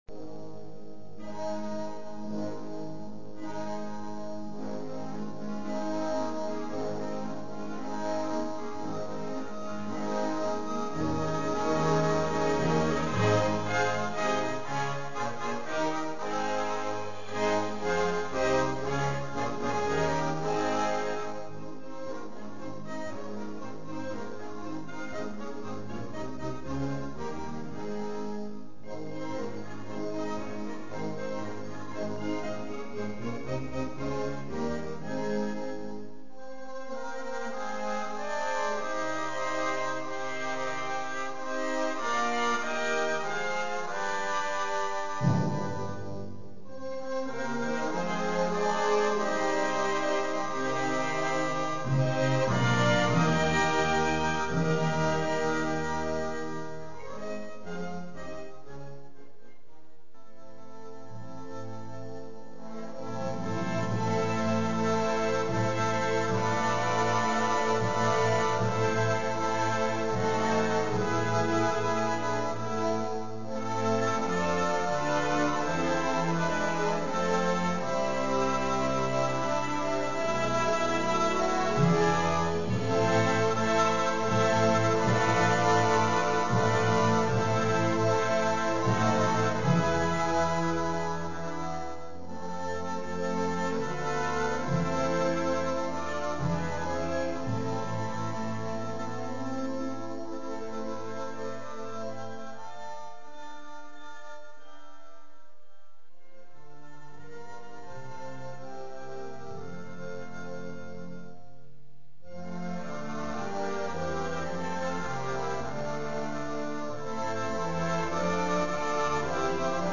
Categoría Banda sinfónica/brass band
Subcategoría Música de Navidad (para orquesta)